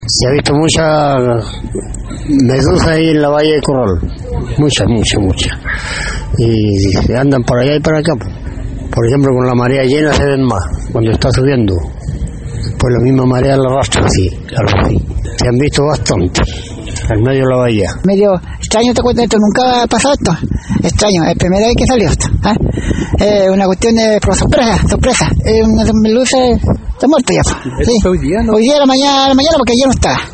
habitantes del sector costero dieron a conocer a Radio Bío Bío su impresión por el inusual fenómeno para las costas de la zona.